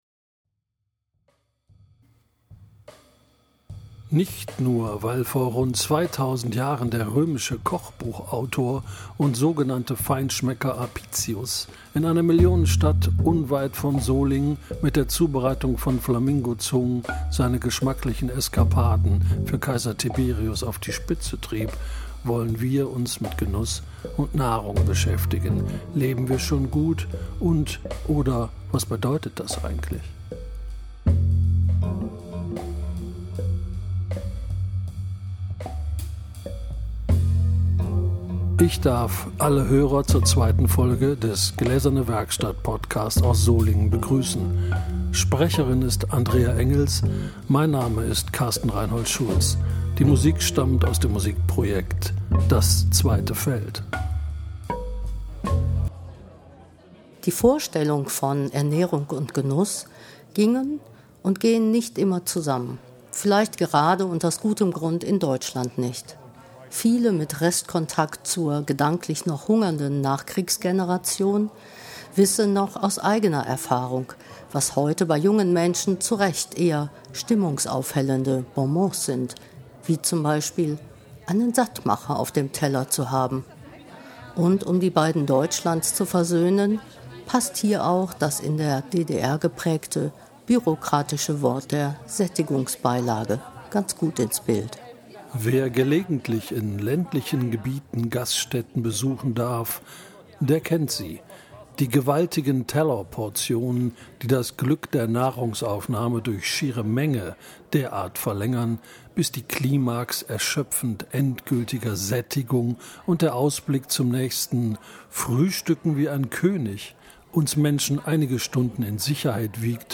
Eine neue Podcastfolge der Gläsernen Werkstatt Solingen, diesmal zum Thema Genuss, Nahrung und Zusammenleben. Mit Überlegungen und O-Tönen rund um den Thementag "Zu Tisch in Mitte", vom 17. Mai 2025.